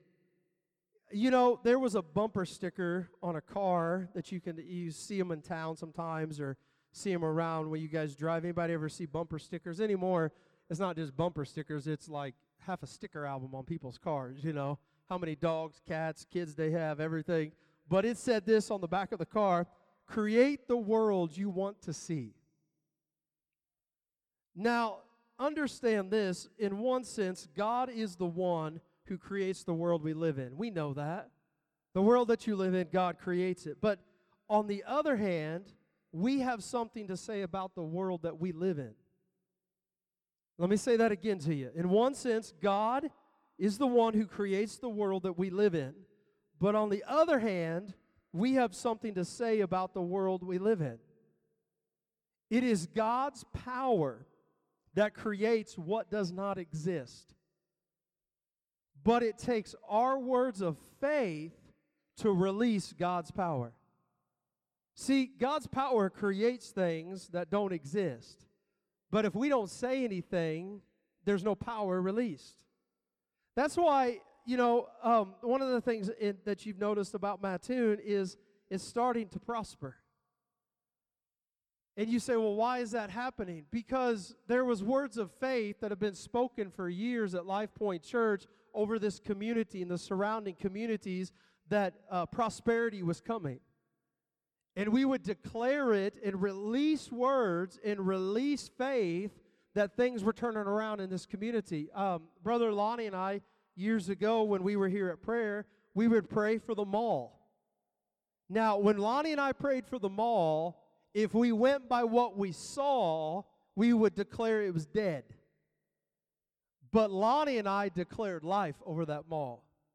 Sermons | LifePointe Church